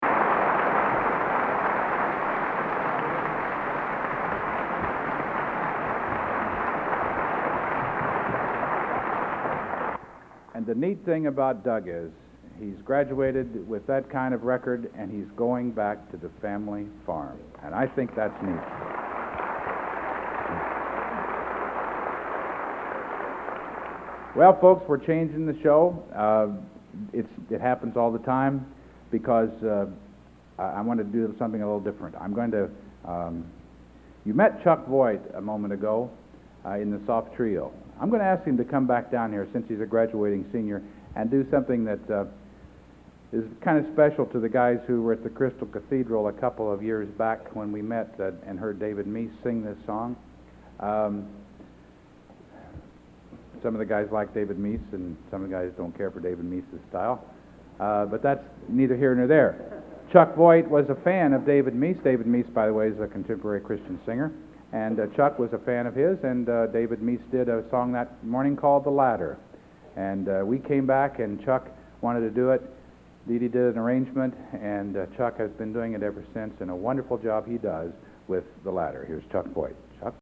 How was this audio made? Collection: End of Season, 1987